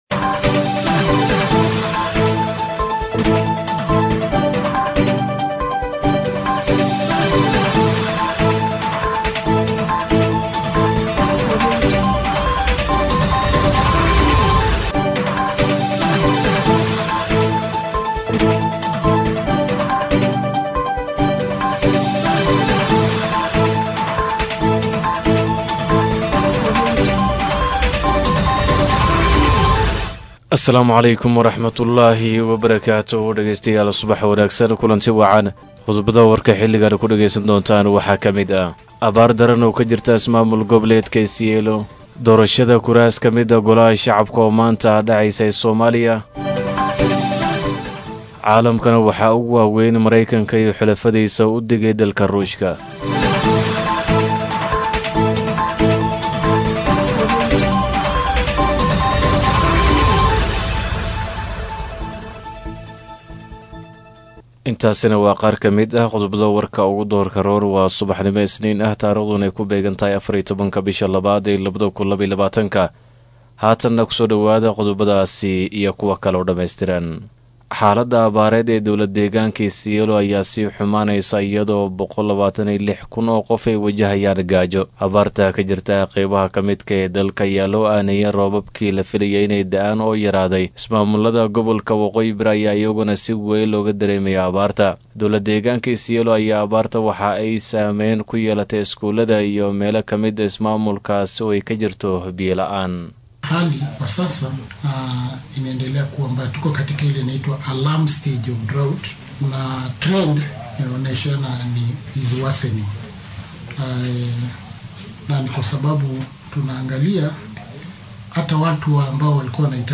DHAGEYSO:WARKA SUBAXNIMO EE IDAACADDA STAR FM